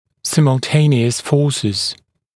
[ˌsɪml’teɪnɪəs fɔːsɪz][ˌсимл’тэйниэс ‘фо:сиз]одновременно действующие силы